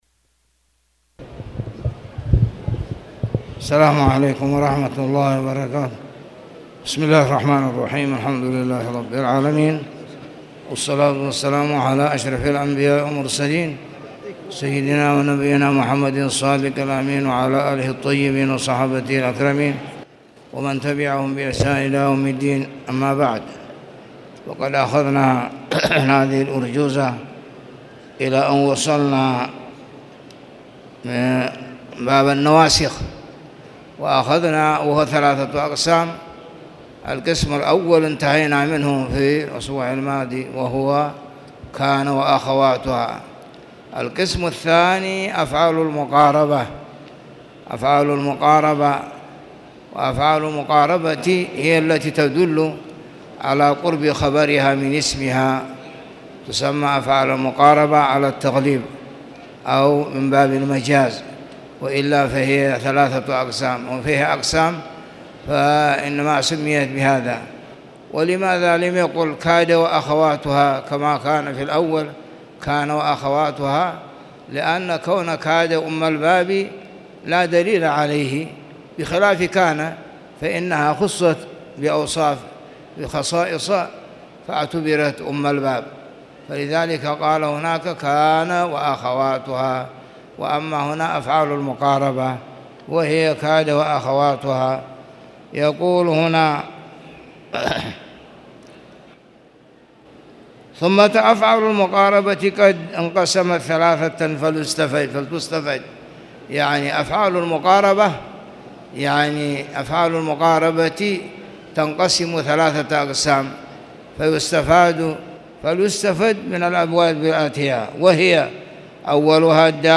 تاريخ النشر ٢٥ محرم ١٤٣٨ هـ المكان: المسجد الحرام الشيخ